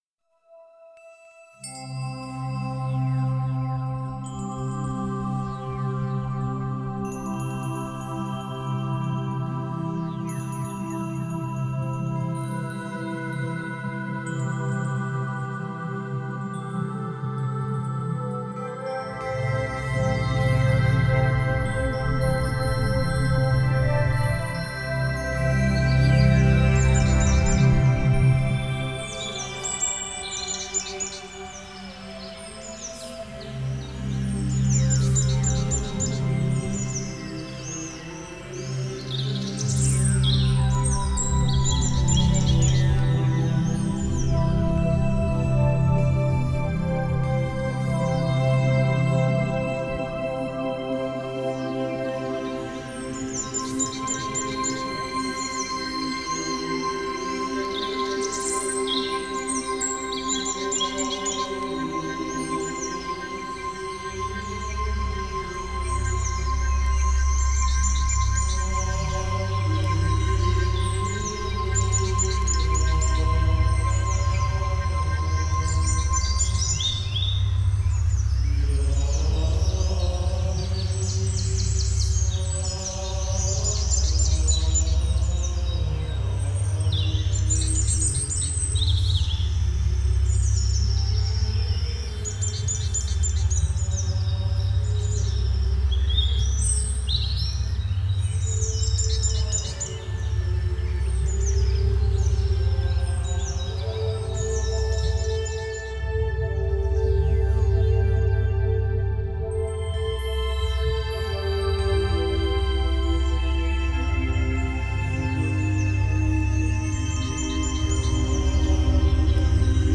Духовная музыка Медитативная музыка Мистическая музыка